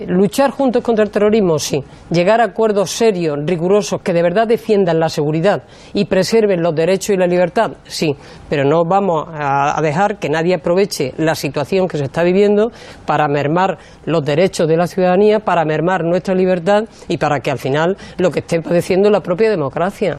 Fragmento de la entrevista de Rosa Aguilar en Los Desayunos de TVE del 16/1/2015